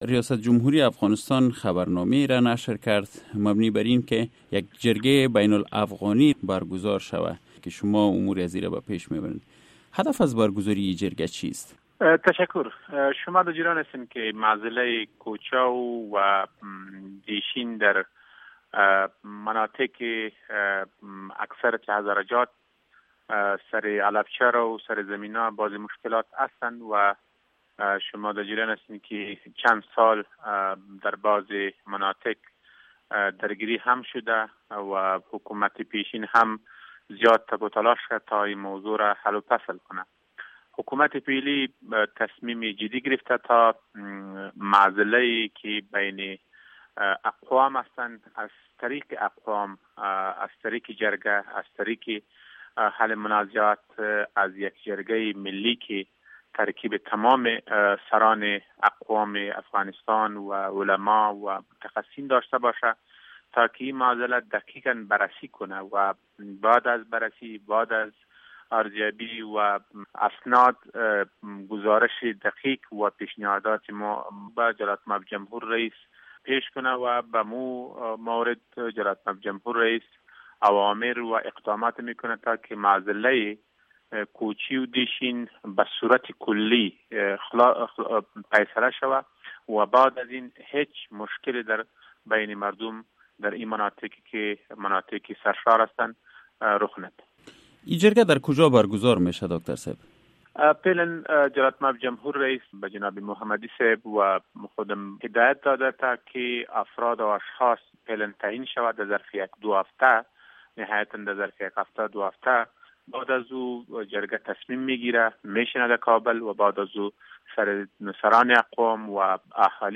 شرح کامل مصاحبه با محمد اکرم خپلواک را در اینجا بشنوید